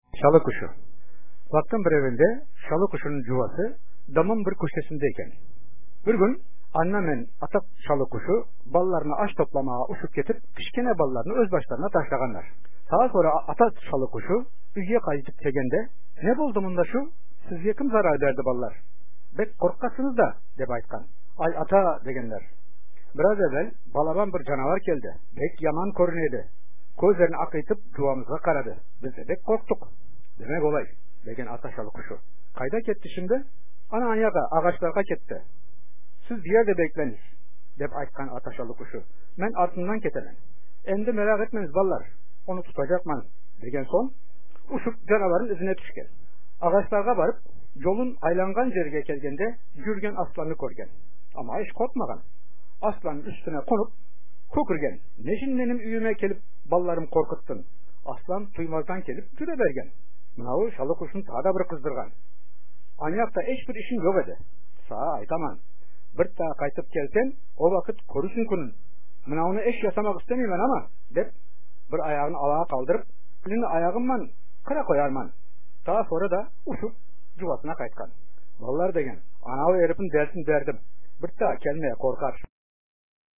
Listen to this translation narrated with native pronunciation: